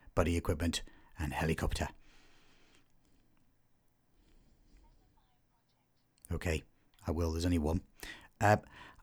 The attached clip has invisible clicks and noise (not hiss or yeti curse actul noise produced by controller etc) nothing shows on the wave.
The clip appears perfectly clean here. Given that it’s a theatrical passage, has breathing noises and an off-mic second voice. The actual silence between breaths is in the -60 range which is about right for a Yeti, and it’s gentle hiss noise.
Conspiratorial sotto-voce is nice, but it doesn’t tell us anything about the system vocal range.